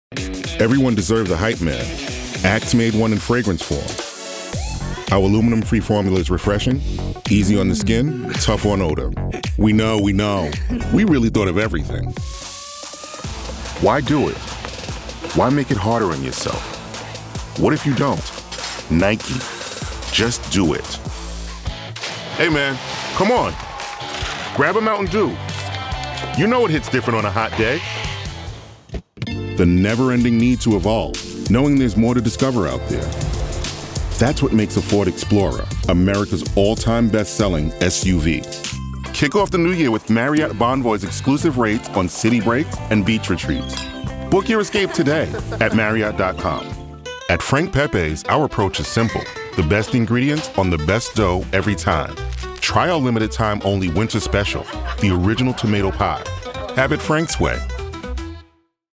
Voice Actor
Commercial Demo